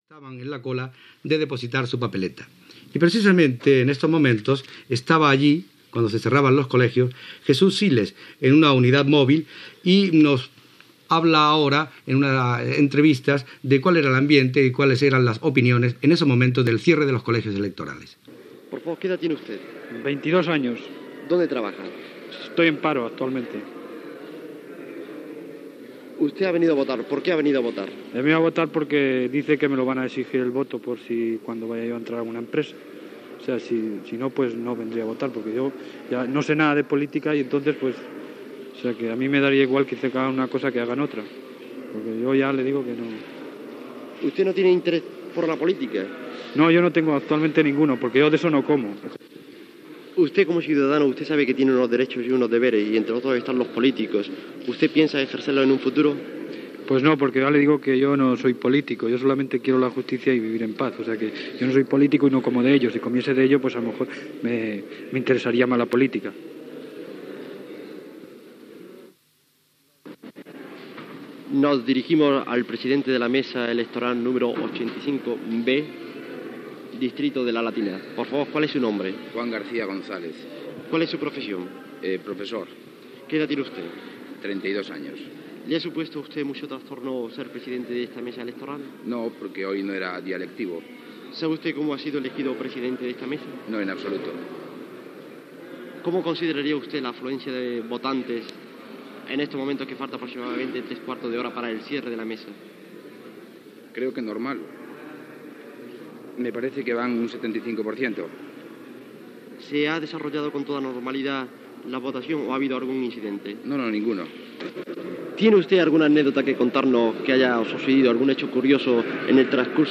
Entrevistes en un col·legi electoral del barri de La Latina de Madrid el dia del referèndum sobre la Llei de reforma política
Informatiu